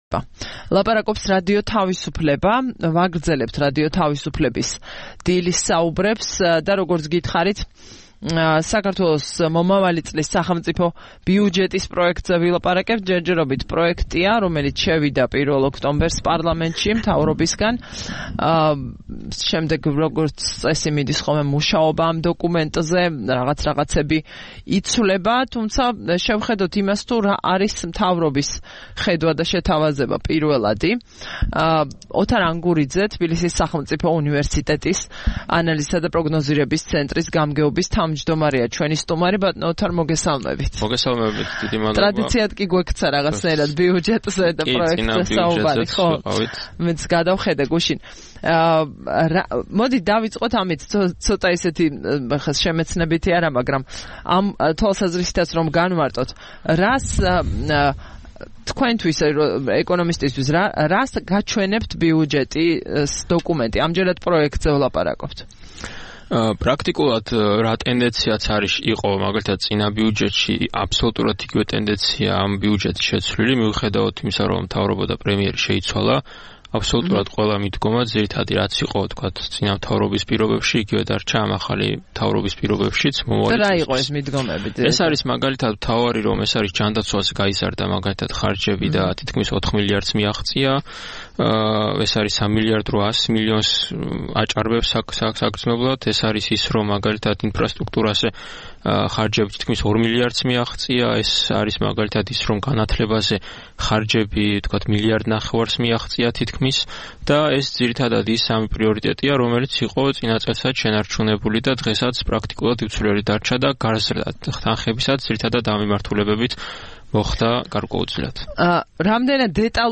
რადიო თავისუფლების "დილის საუბრების" სტუმარი